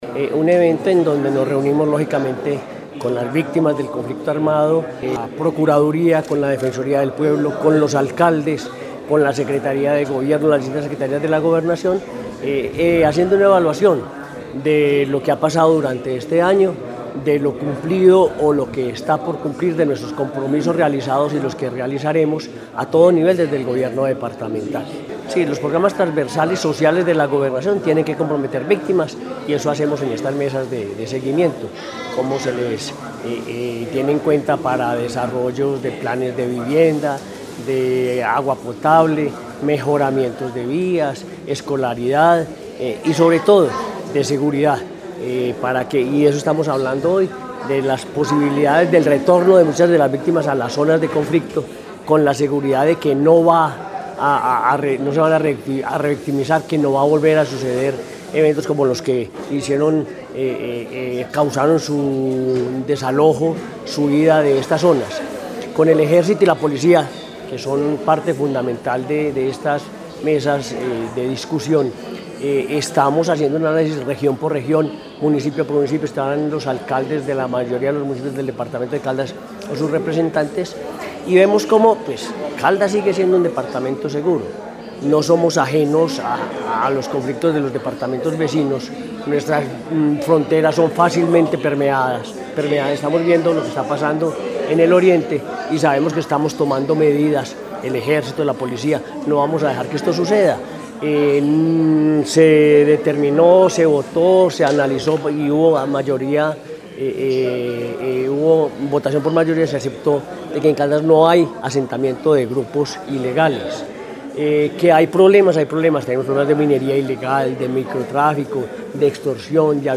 Henry Gutiérrez Ángel, gobernador de Caldas.
Henry-Gutierrez-Angel-gobernador-de-Caldas-2.mp3